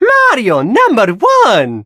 20 KB {{aboutfile |1=An undecipherable sound made from an unknown character.